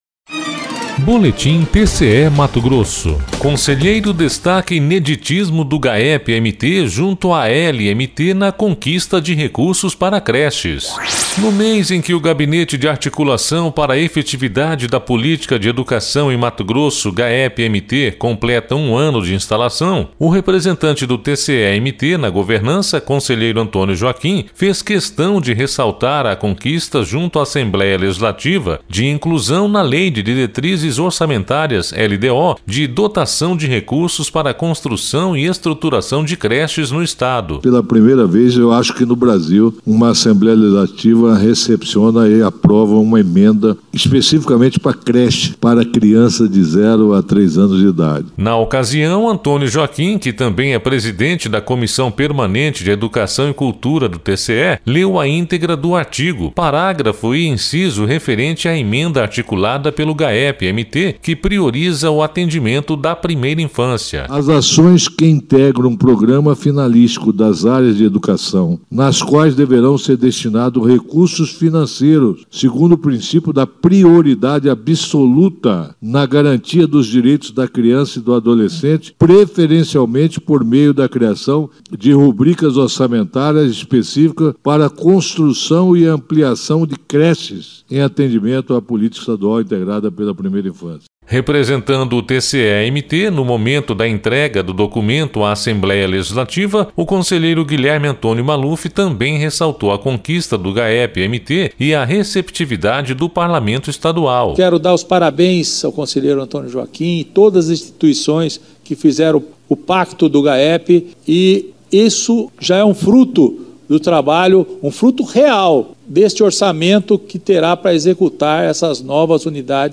Sonora: Antonio Joaquim – conselheiro do TCE-MT
Sonora: Guilherme Antonio Maluf – conselheiro vice-presidente da Copec do TCE-MT
Sonora: José Carlos Novelli - conselheiro presidente do TCE-MT